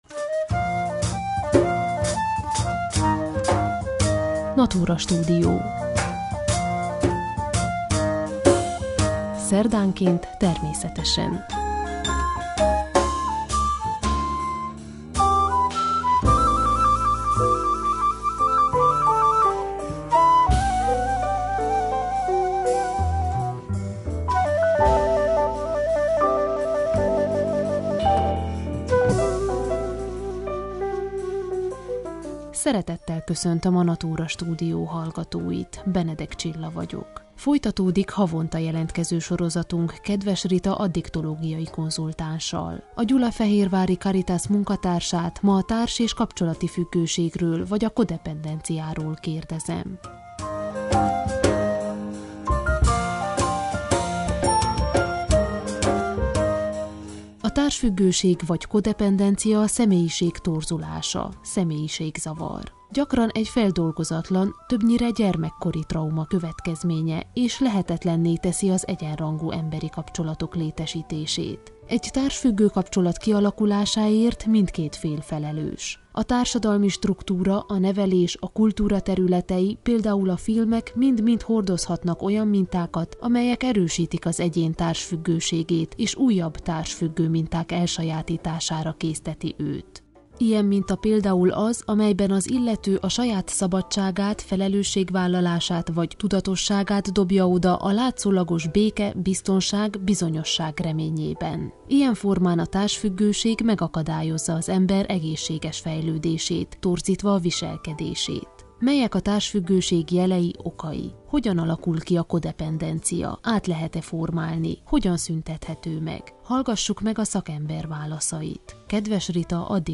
A gyulafehérvári Caritas munkatársával ma a társ- és kapcsolati függőségről vagy a kodependenciáról beszélgetünk.